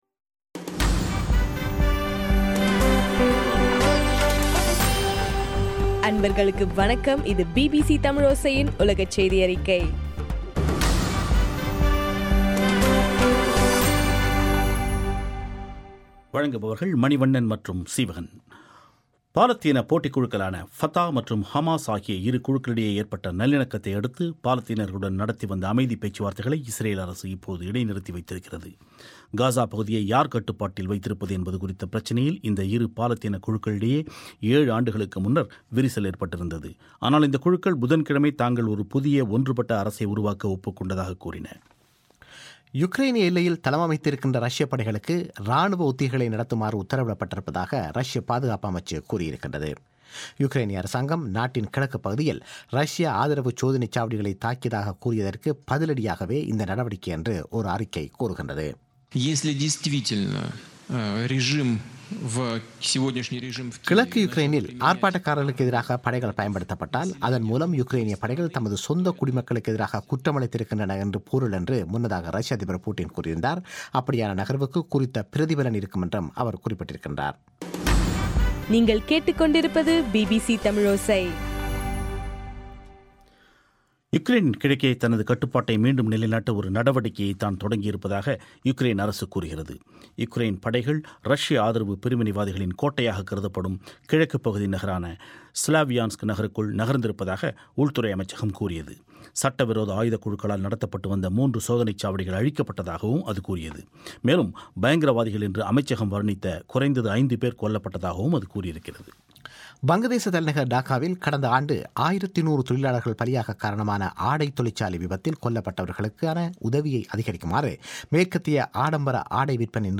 ஏப்ரல் 24 பிபிசியின் உலகச் செய்திகள்